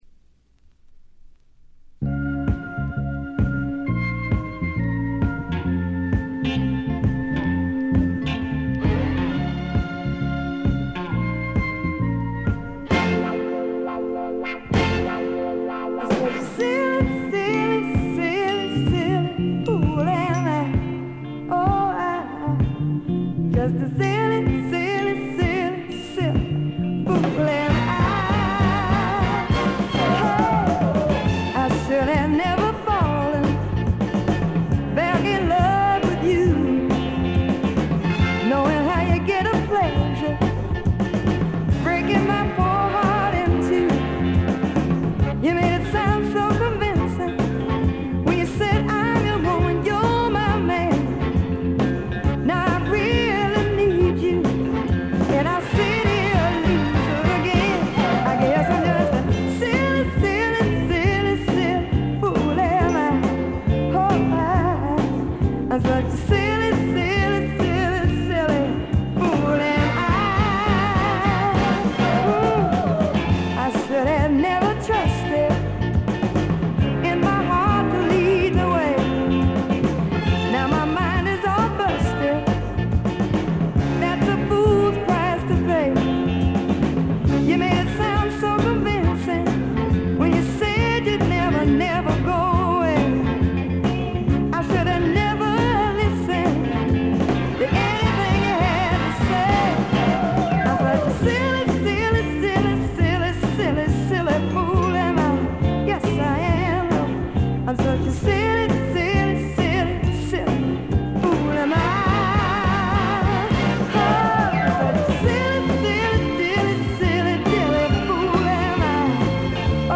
まさしく豪華絢爛なフィリー・サウンドででこれも傑作です。
試聴曲は現品からの取り込み音源です。
Recorded at Sigma Sound Studios, Philadelphia, Pennsylvania.